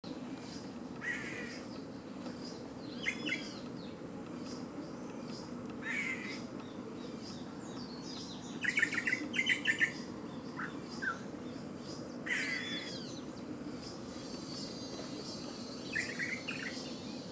Boyero Negro (Cacicus solitarius)
Nombre en inglés: Solitary Cacique
Fase de la vida: Juvenil
Localidad o área protegida: Reserva Ecológica Costanera Sur (RECS)
Condición: Silvestre
Certeza: Fotografiada, Vocalización Grabada
boyero-negro.mp3